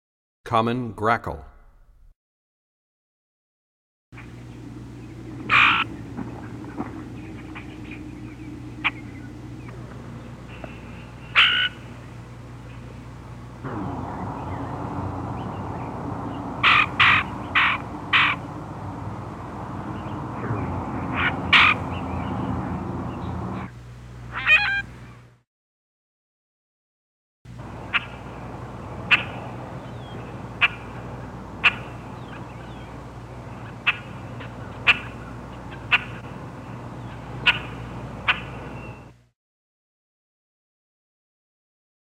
26 Common Grackle.mp3